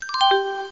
beep.mp3